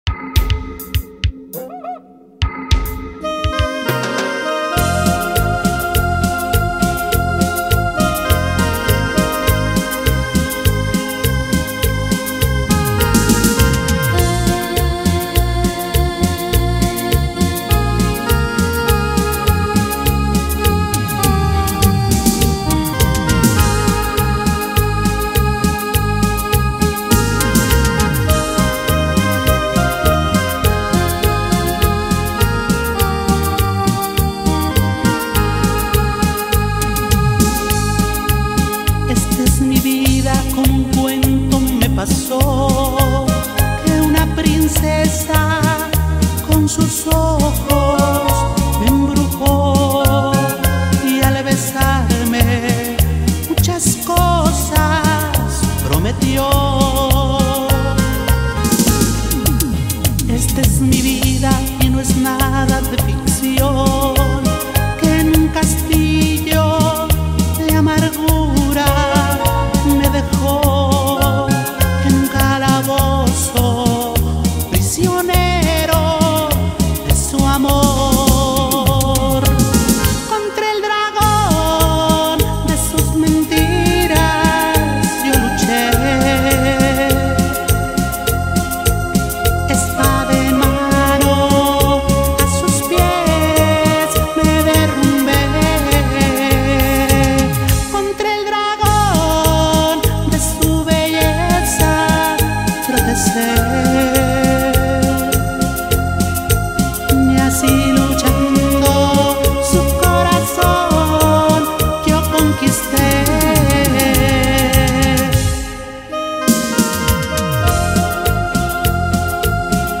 Carpeta: Cumbia y + mp3